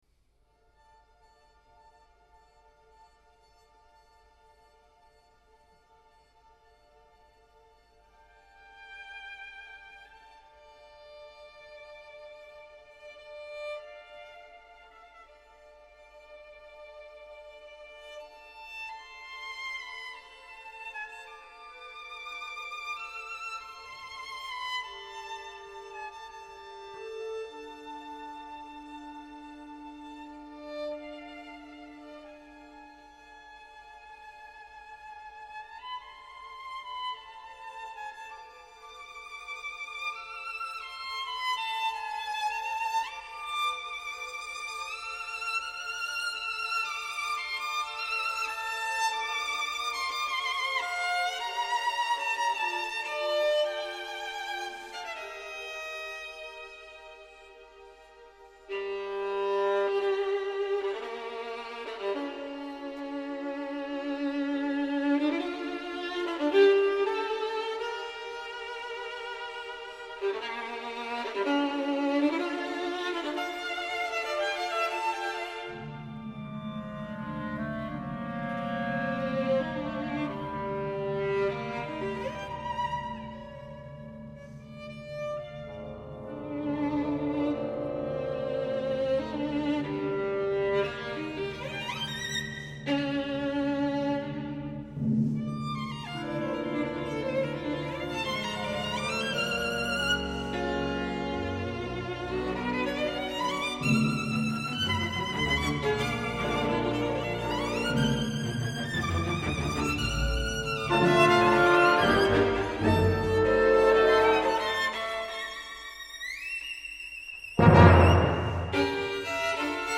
L’abbiamo incontrata ospite del Teatro alla Scala , reduce dalla prova generale dell’attesissimo Concerto in re maggiore op. 61 per violino e orchestra di Ludwig van Beethoven, che l’ha vista di nuovo sul palco del Piermarini insieme a Daniel Barenboim e la Filarmonica della Scala (con loro nel novembre 2014 inaugurava la stagione della Filarmonica con il Concerto di Tchaikovsky).